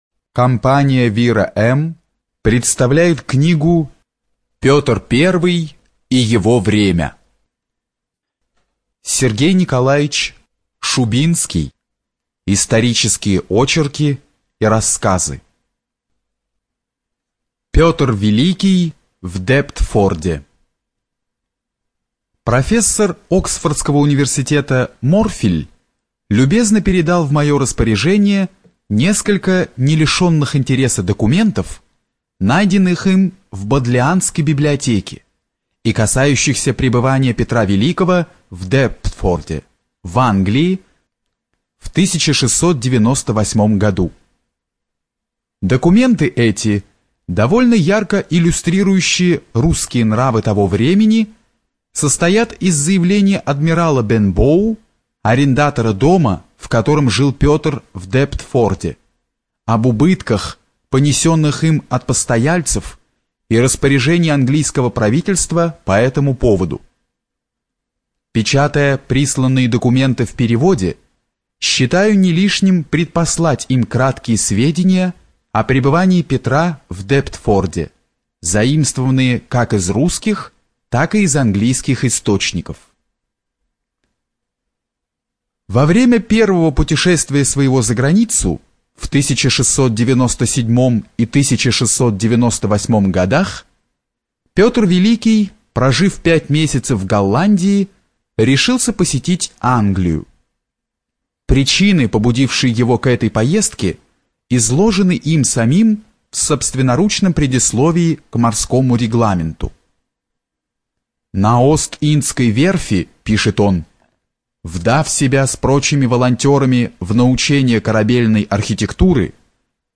Студия звукозаписиВира-М